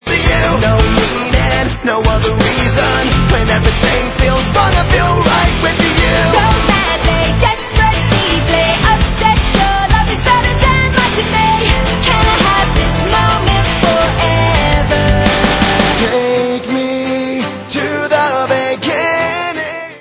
sledovat novinky v oddělení Alternative Rock
Rock